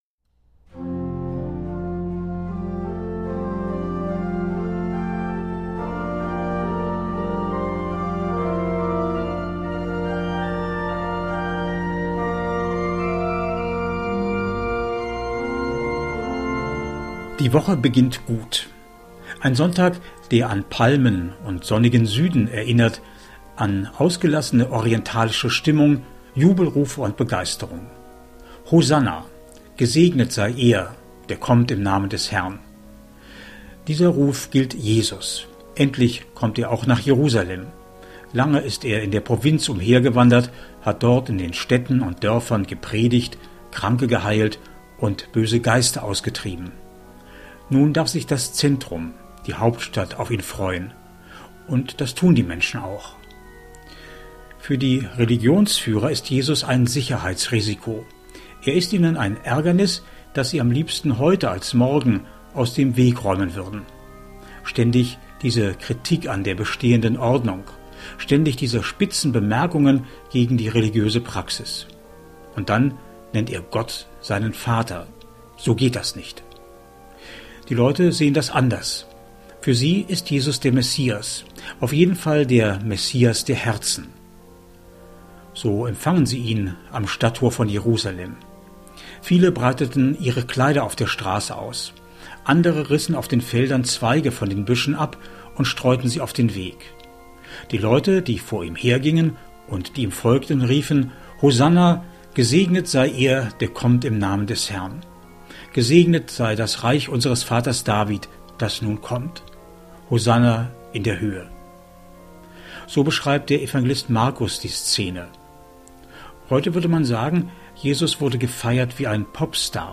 MEDITATION